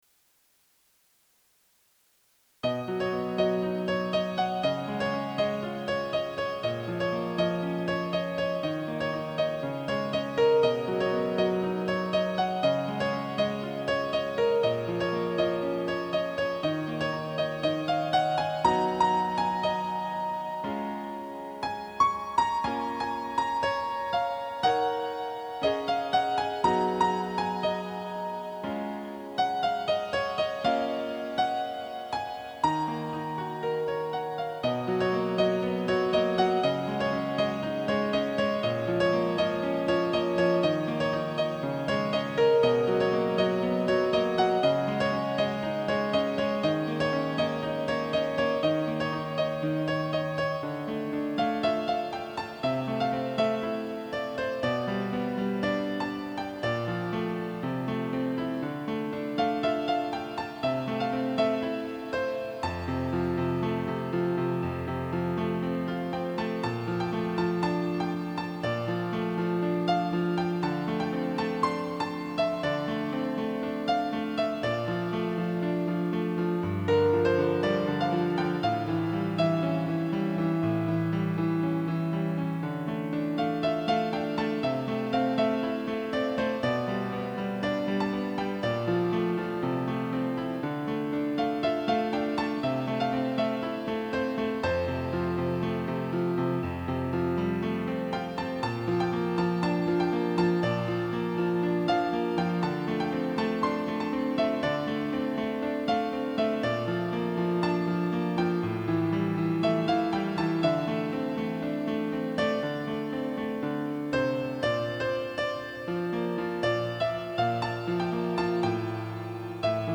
原曲の雰囲気を活かして、簡単なピアノアレンジにしています。